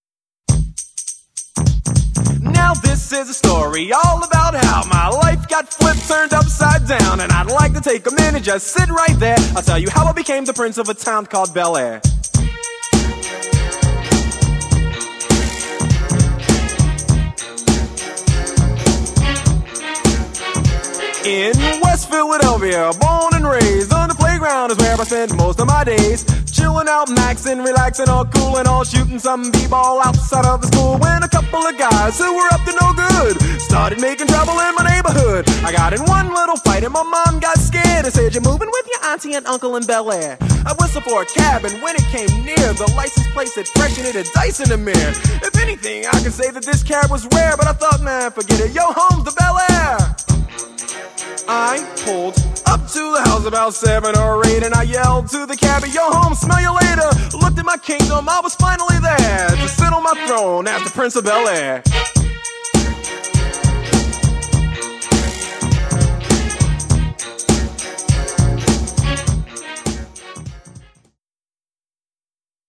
Theme Song (WAV 12.9 MB)